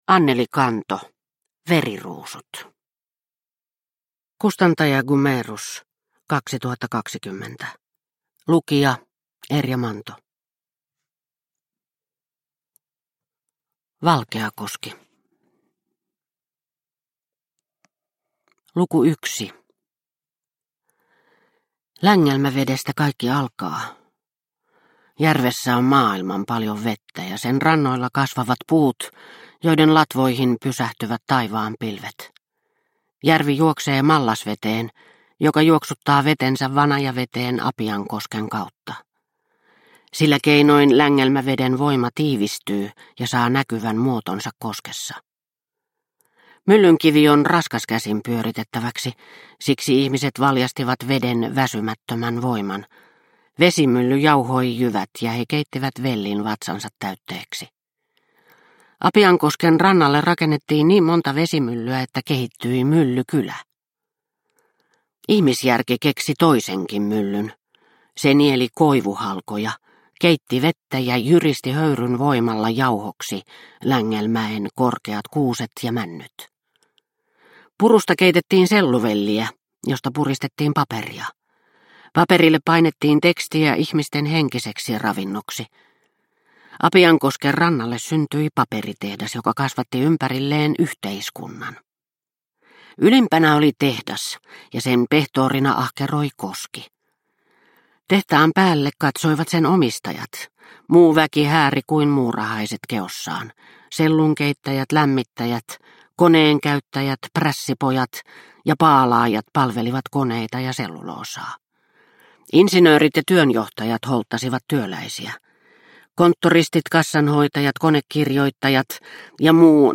Veriruusut – Ljudbok – Laddas ner